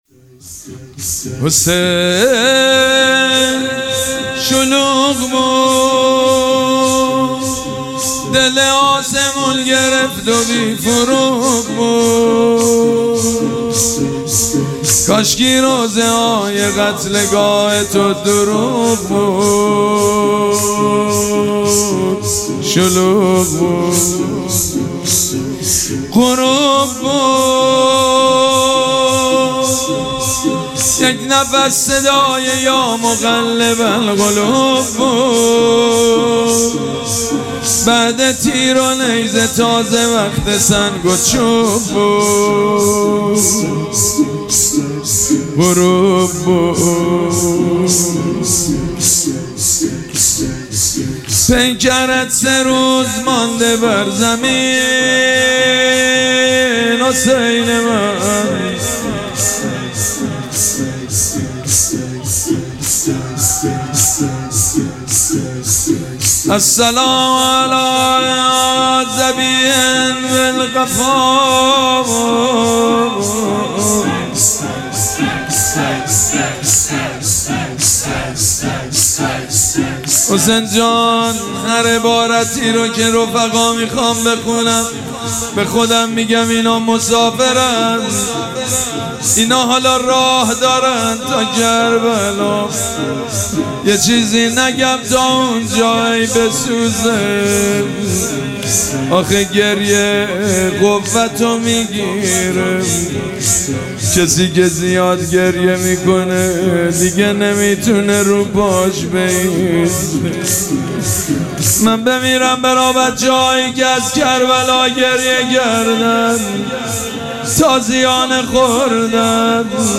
شب اول مراسم عزاداری اربعین حسینی ۱۴۴۷
مداح
حاج سید مجید بنی فاطمه